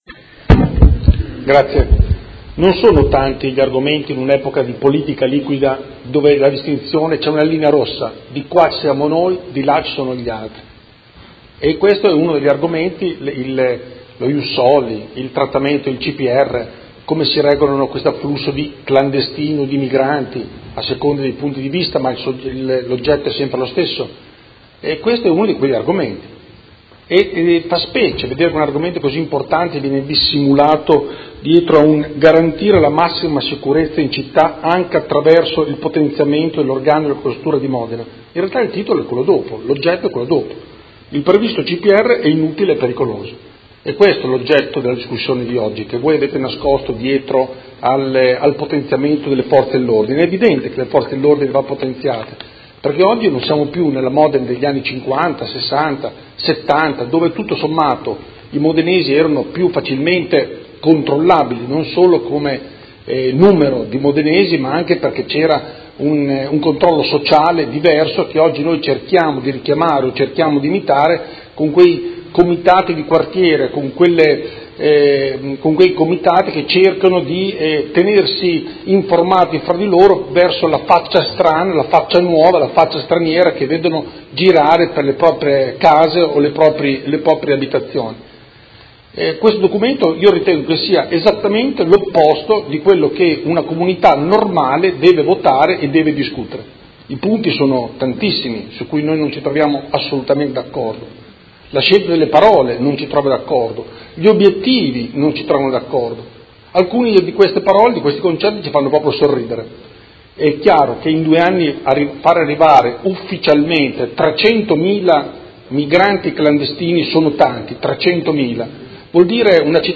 Andrea Galli — Sito Audio Consiglio Comunale
Seduta del 12/04/2018 Dibattito. Ordine del giorno 54394, Emendamento 54490 e ordine del giorno 54480 sulla sicurezza.